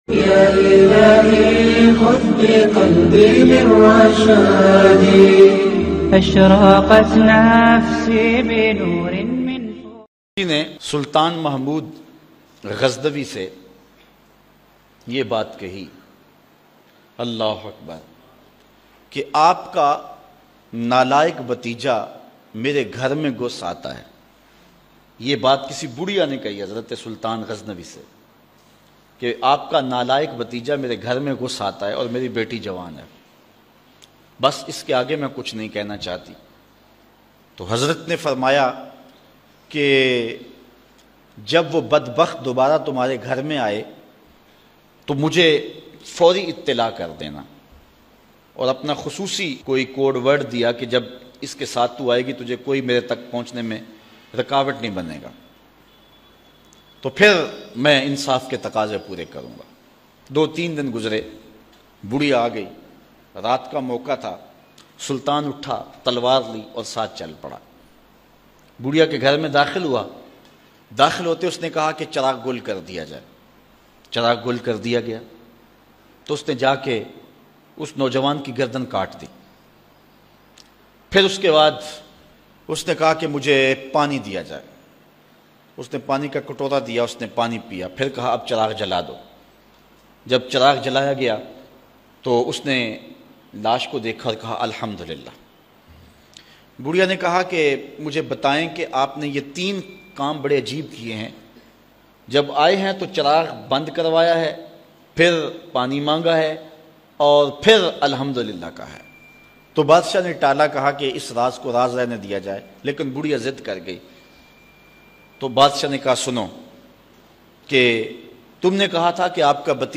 Nojawan Larki Aur Uski Maa Ki Faryad bayan mp3